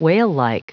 Prononciation du mot whalelike en anglais (fichier audio)
Prononciation du mot : whalelike